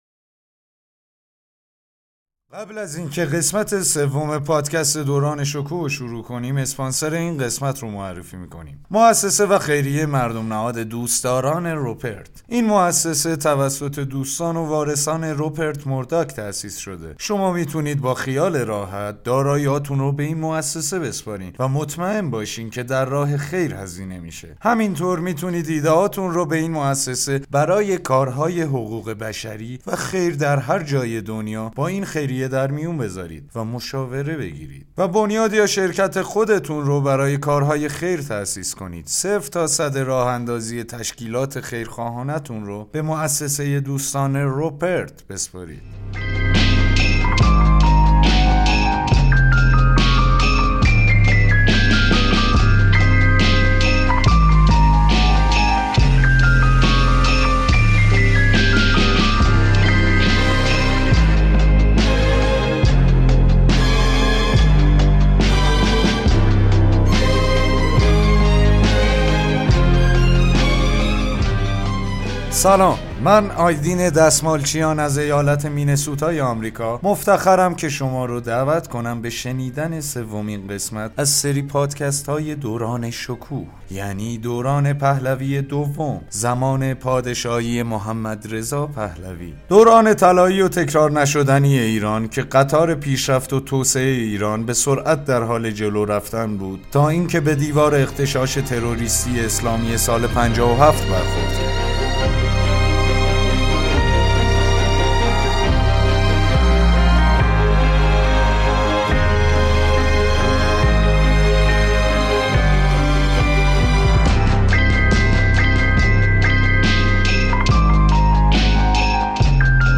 گوینده ی آنتونی پارسونز